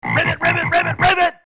Which frog is green? The mic is a SM58 placed 12" from the frogs, recorded dry, no EQ. I noticed that my MP3 encoder killed some of the high end, but c'est la vie.